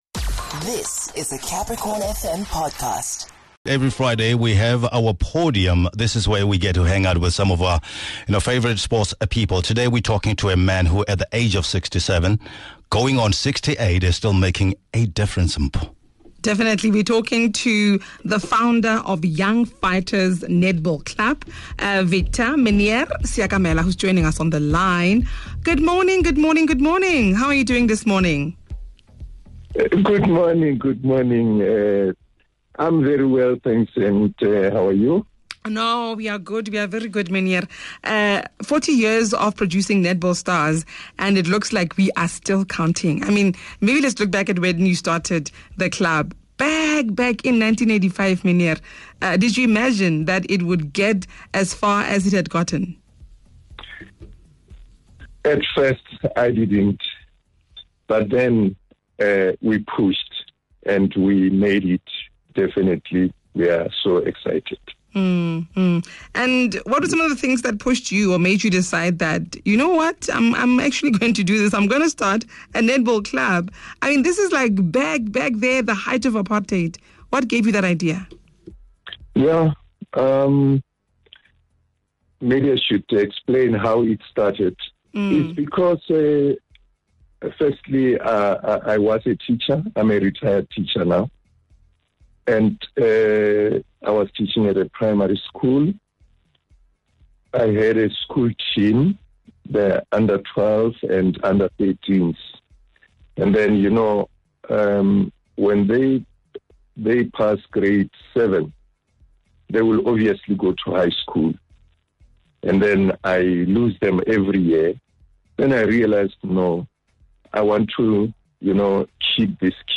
They get to know him better and hear from some of his former learners.